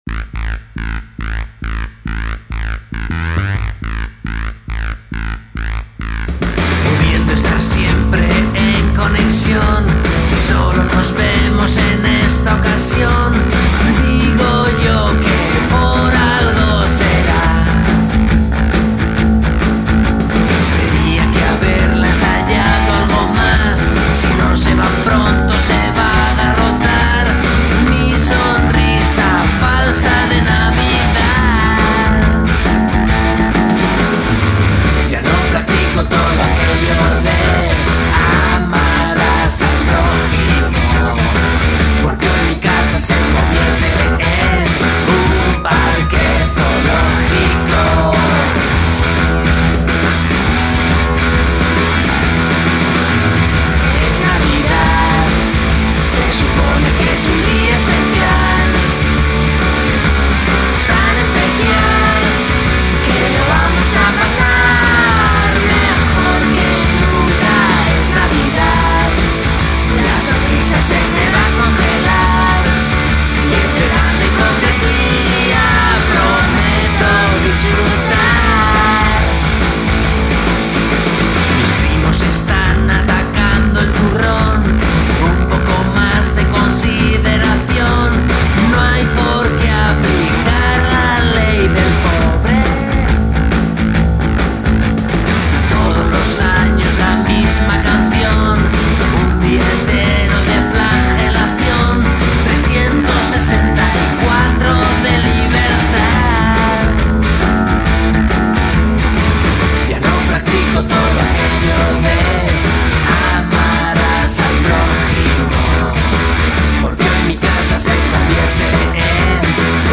canción navideña